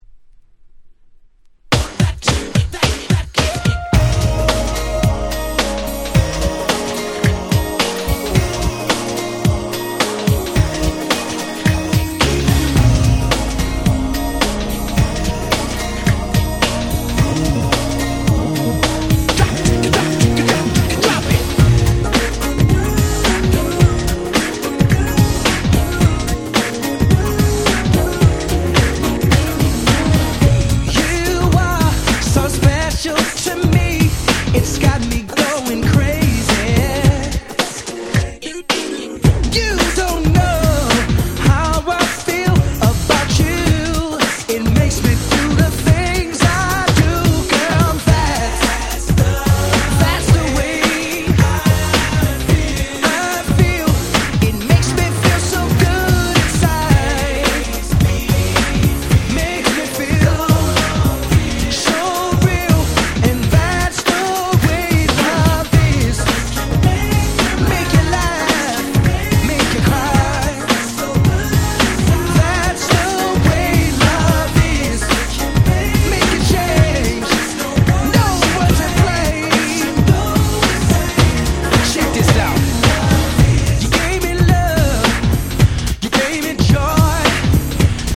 New Jack Swing